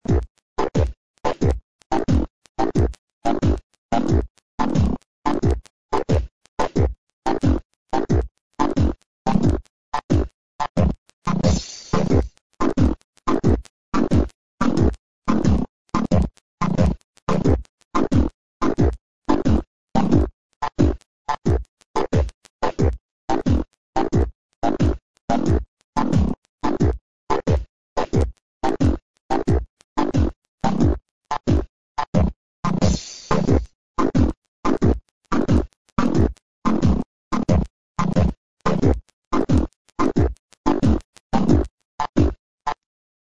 Quasi-ska, at least. It sounds kind of dumb because my software can't handle the concept of 6/8 time, so I had to make do with dotted quarter-eighth combinations.